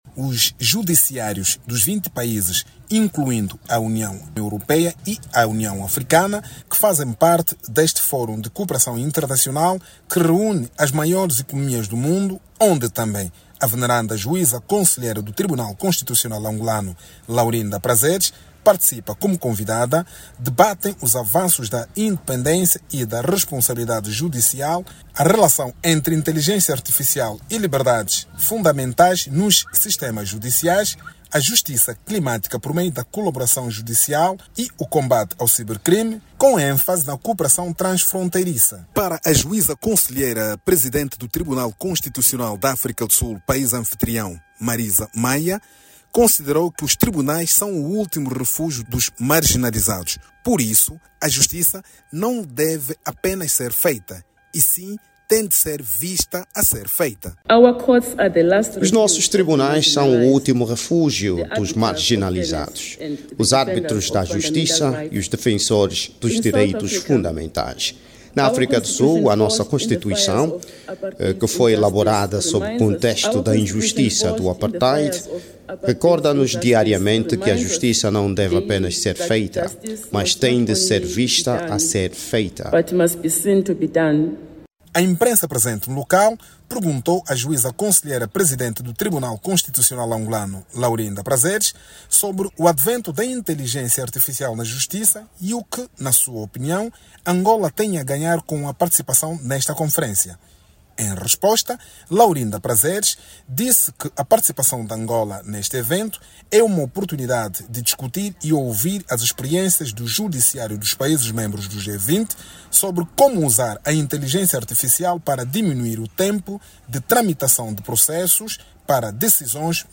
reportagem
a partir da África do Sul